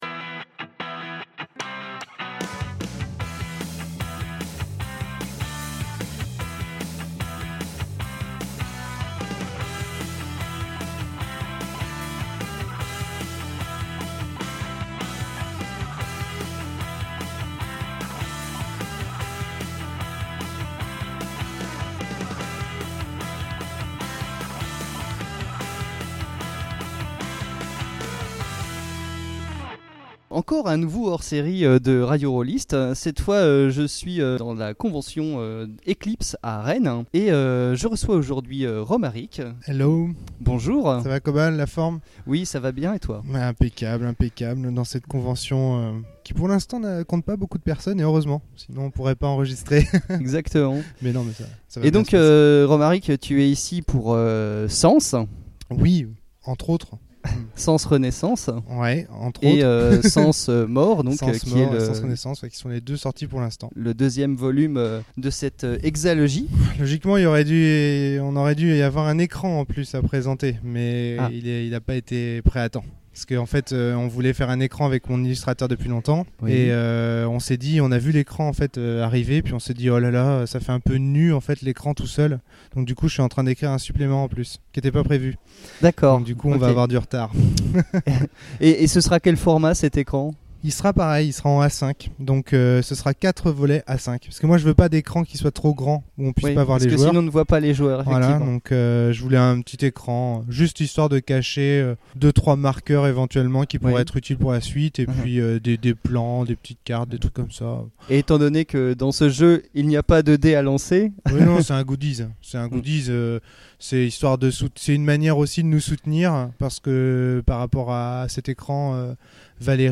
Eclipse : Interview